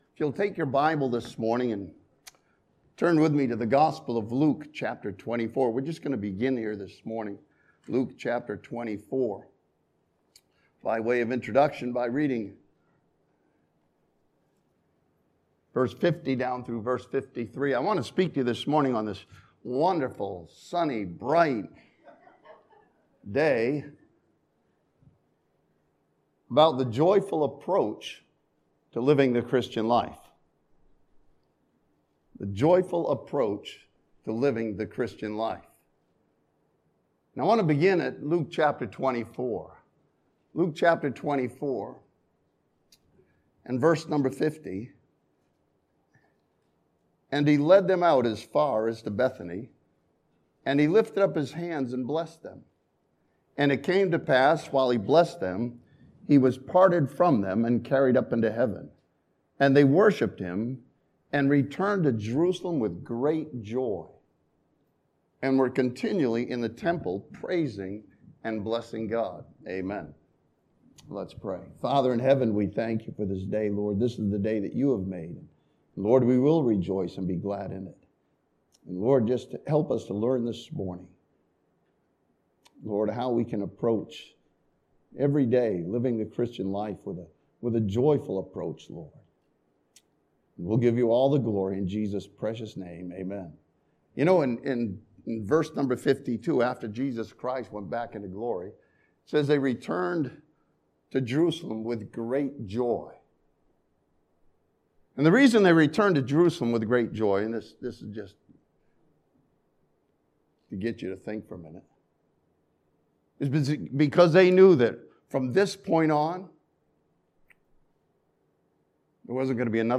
This sermon from Romans chapter 5 challenges the believer to continue rejoicing in tribulation.